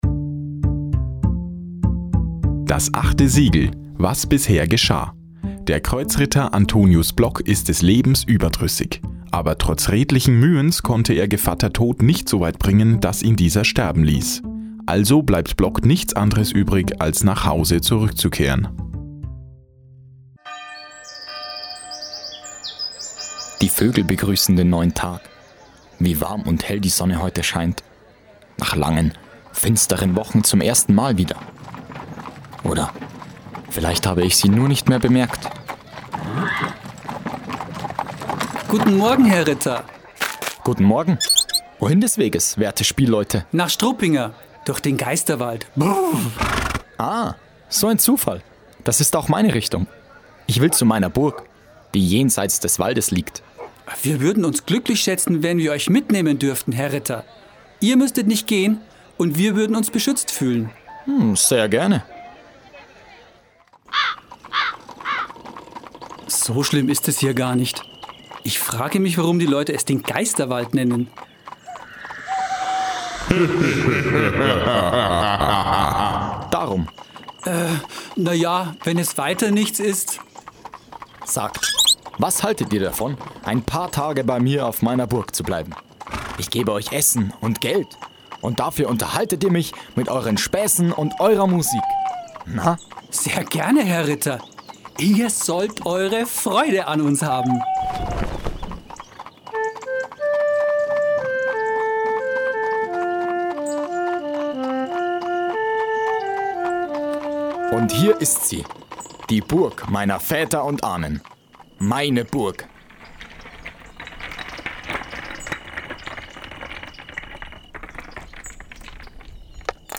16. Hörspiel
hoerspiel_dasAchteSiegel_teil3.mp3